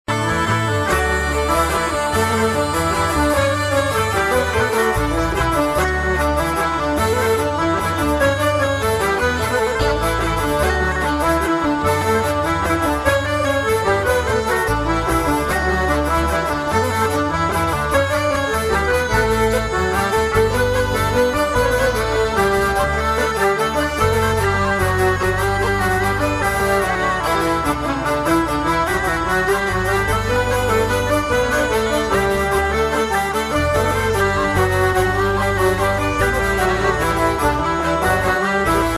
Key: D
Form: Mazurka
Played “at a more danceable tempo” by the composer’s band
M:3/4